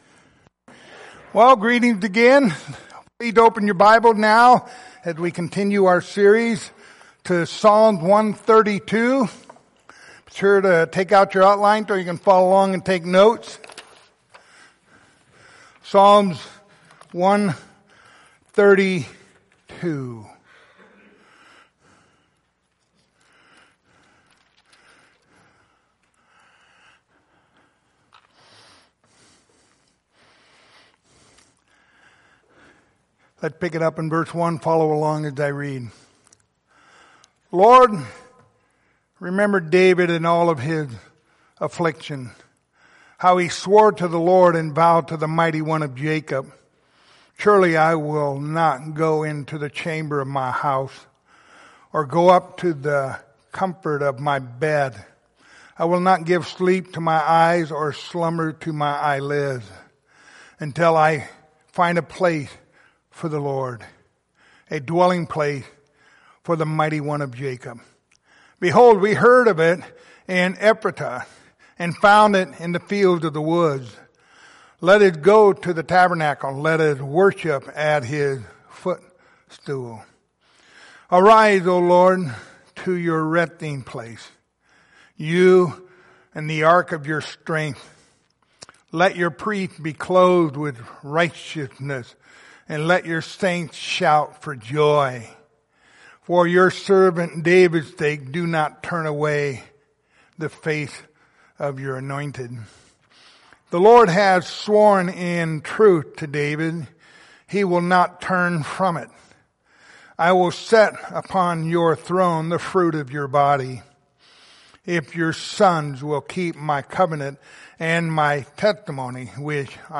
Passage: Psalm 132:1-18 Service Type: Sunday Morning Topics